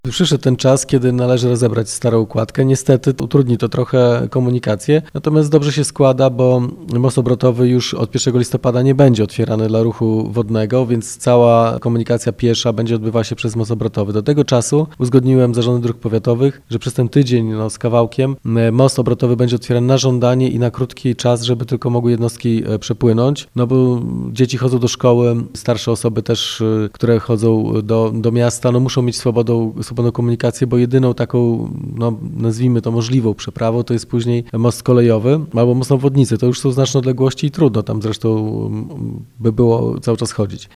Będą z niej mogli korzystać piesi i rowerzyści a w nagłych przypadkach pojazdy służb ratowniczych – mówi Wojciech Iwaszkiewicz, burmistrz Giżycka.